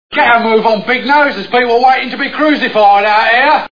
Monty Python's Life of Brian Movie Sound Bites